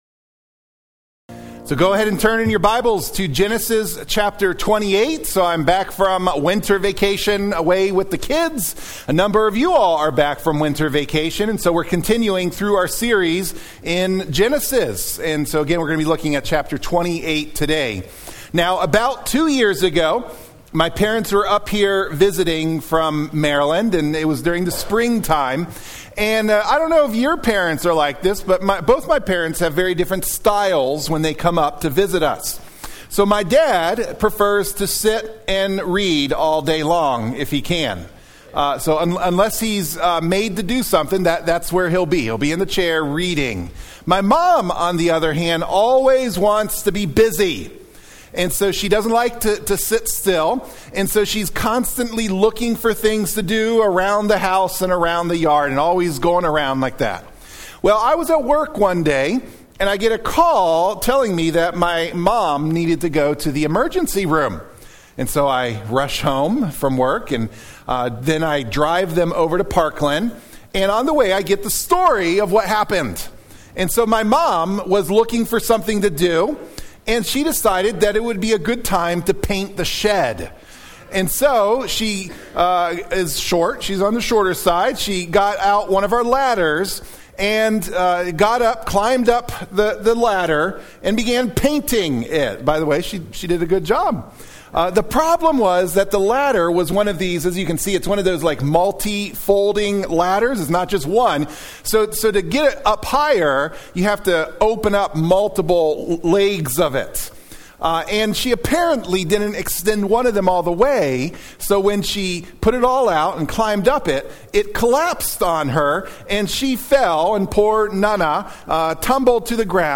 Sermon-3-9-25-MP3-for-Audio-Podcasting.mp3